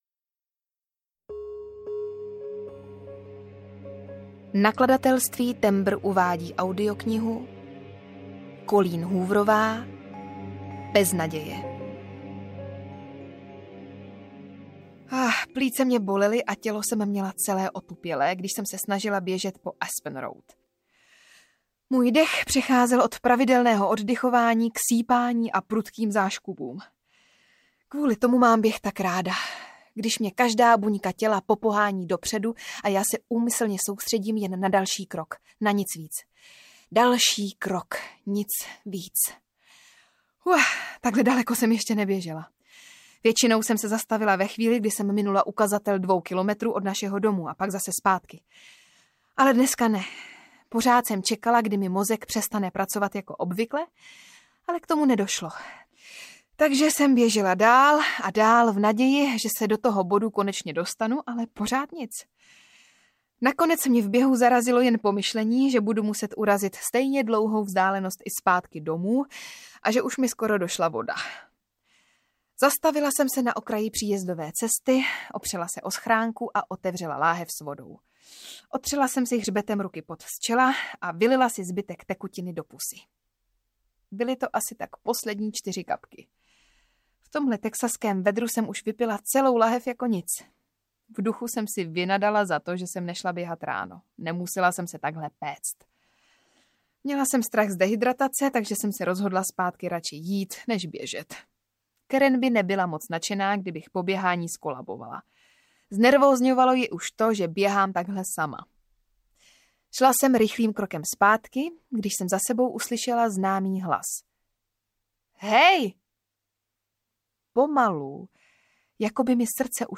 Bez naděje audiokniha
Ukázka z knihy
• InterpretVeronika Khek Kubařová